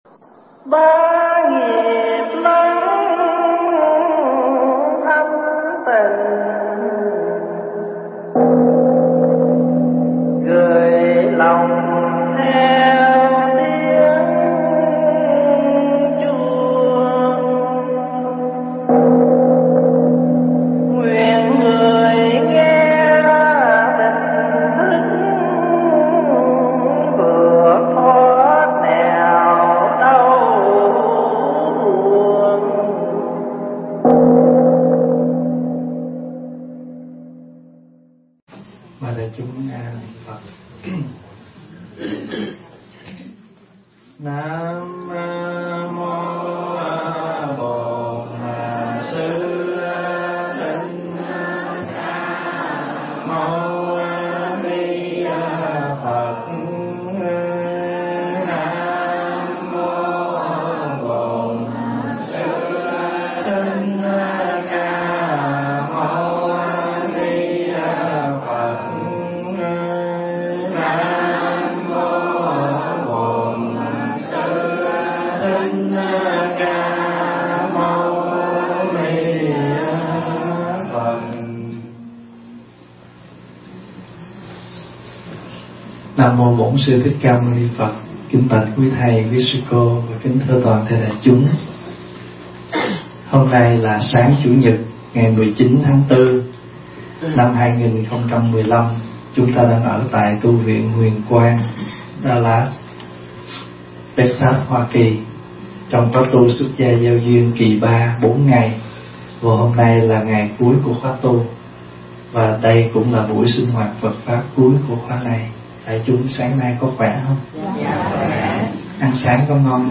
thuyết giảng tại Tu Viện Huyền Quang trong khóa tu xuất gia gieo duyên, ngày 19 tháng 4 năm 2015